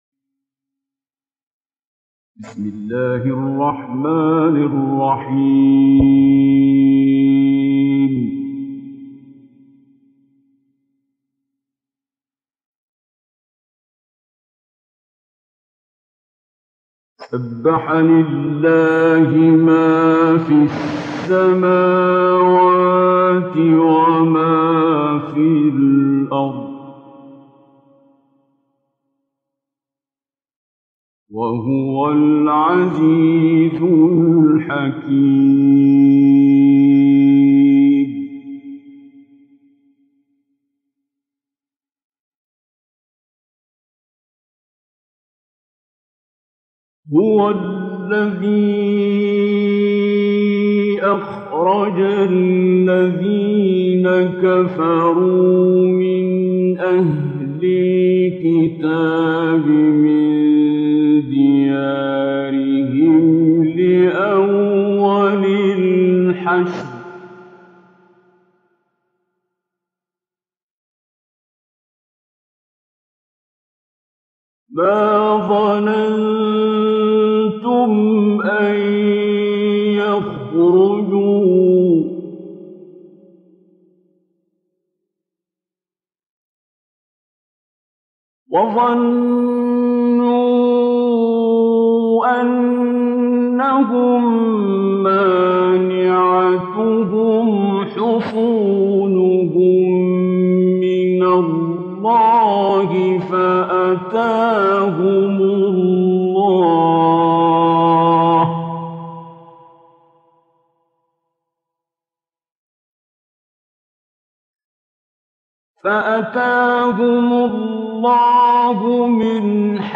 دانلود تلاوت زیبای سوره مبارکه حشر آیات ۱ الی ۱۲ با صدای دلنشین شیخ عبدالباسط عبدالصمد
در این بخش از ضیاءالصالحین، تلاوت زیبای آیات 1 الی 12 سوره مبارکه حشر را با صدای دلنشین استاد شیخ عبدالباسط عبدالصمد به مدت 29 دقیقه با علاقه مندان به اشتراک می گذاریم.